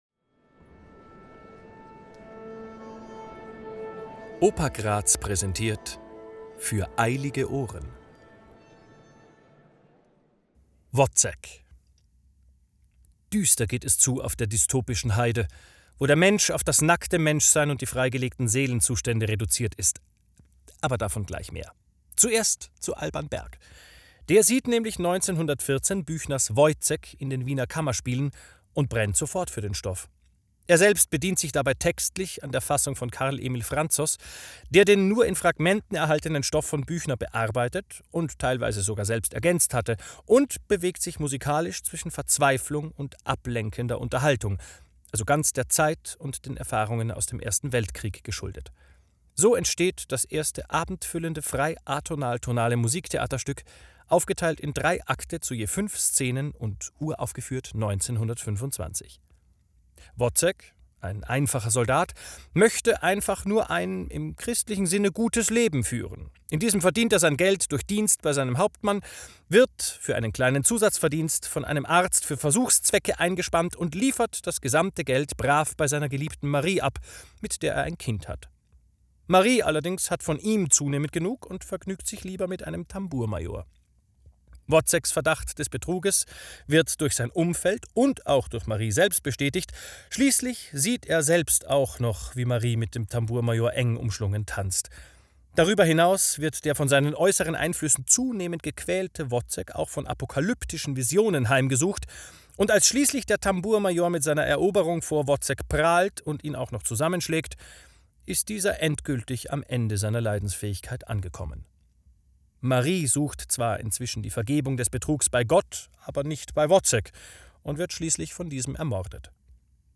»Für eilige Ohren« – die Audioeinführung der Oper Graz!